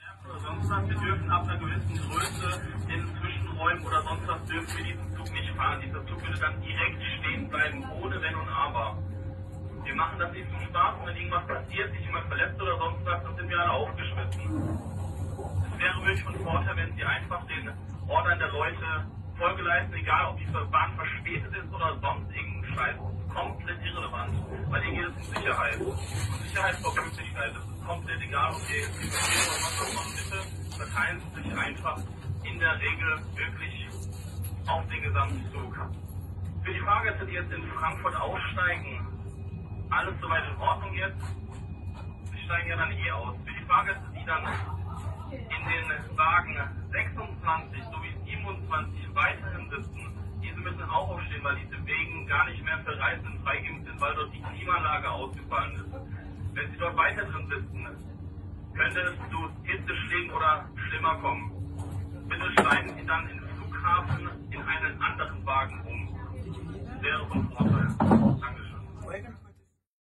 Was ihr jetzt hört ist kein Scherz es ist die Wirklichkeit: Die deutsche Bahn sagt der Zug fährt nicht weiter bis die Passagiere sich im ganzen Zug verteilen zur Sicherheit wegen Hitzeschlag Gefahr.